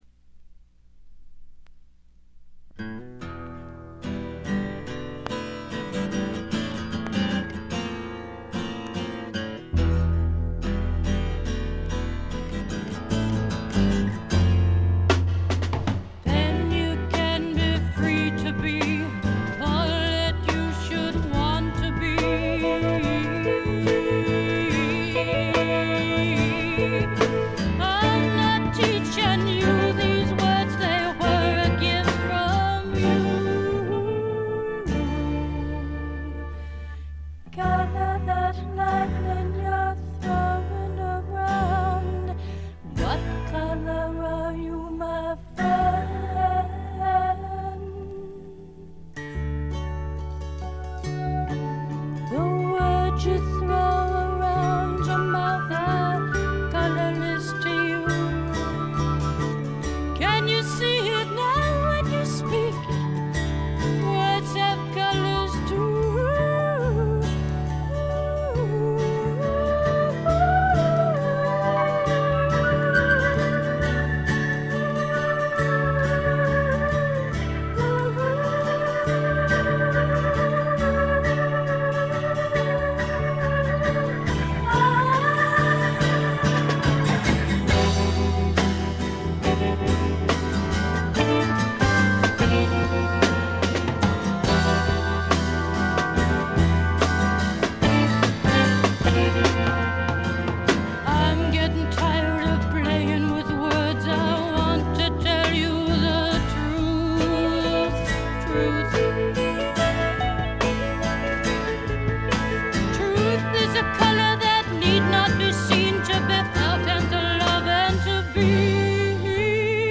わずかなノイズ感のみ。
試聴曲は現品からの取り込み音源です。
Vocal, Guitar